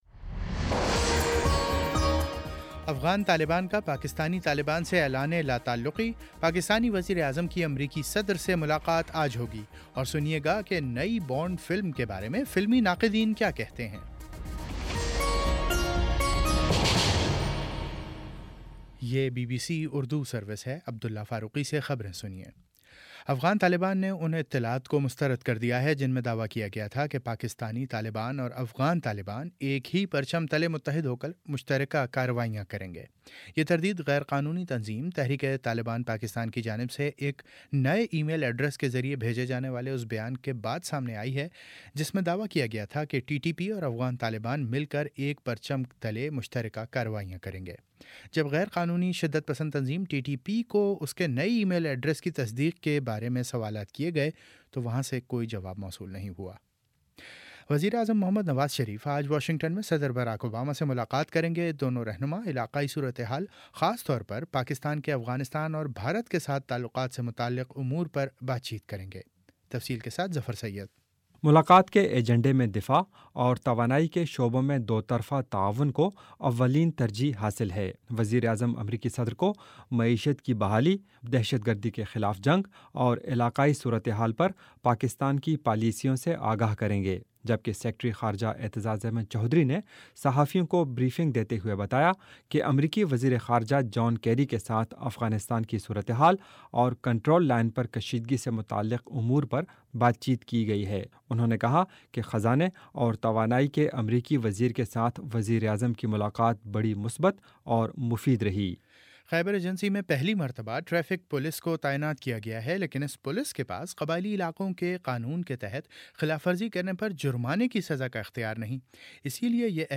اکتوبر 22 : شام چھ بجے کا نیوز بُلیٹن